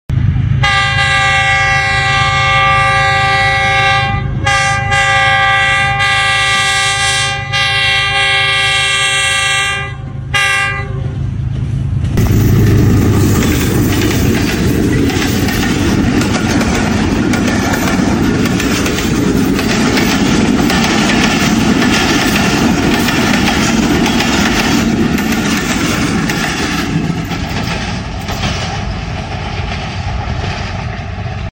Arrival Of Fareed Express 38dn Sound Effects Free Download